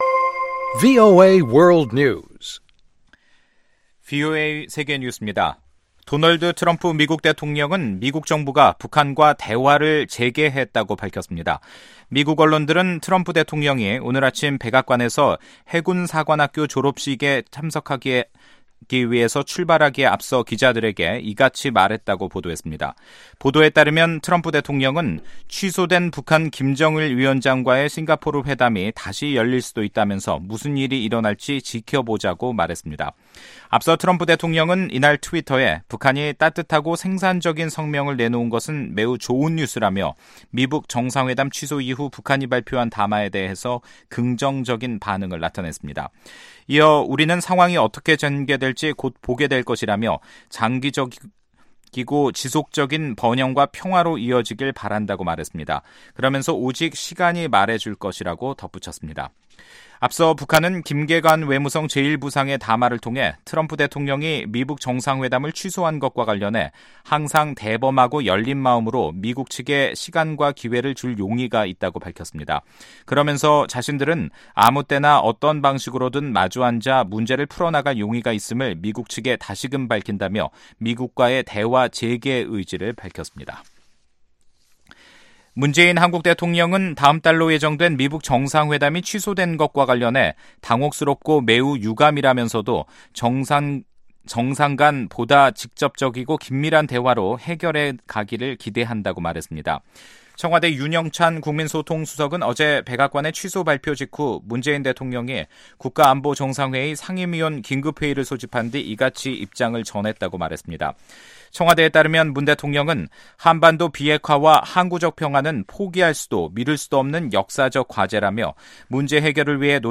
VOA 한국어 간판 뉴스 프로그램 '뉴스 투데이', 2018년 5월 25일 3부 방송입니다. 도널드 트럼프 대통령이 다음 달 12일로 예정된 미북 정상회담을 취소했습니다. 북한은 미국의 회담 취소 통보에 놀라고 유감이지만 여전히 마주 앉을 용의가 있다고 밝혔습니다.